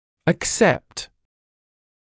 [ək’sept][эк’сэпт]принимать, соглашаться